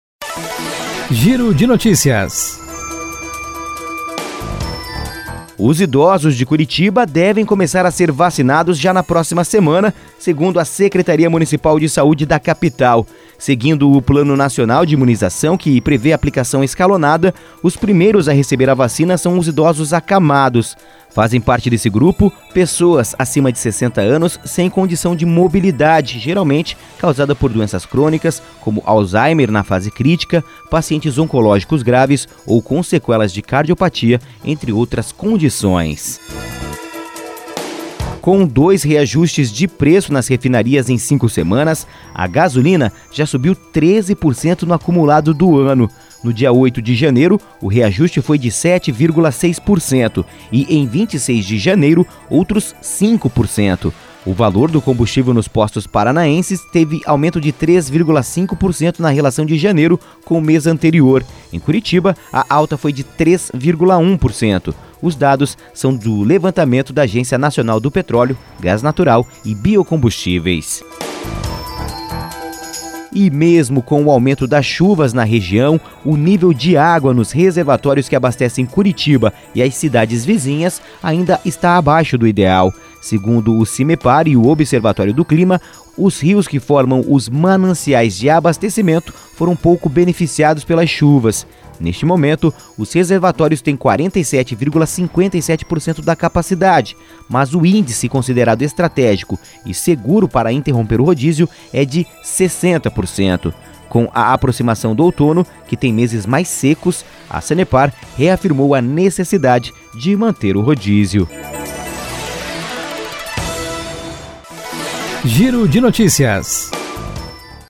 Giro de Notícias (COM TRILHA)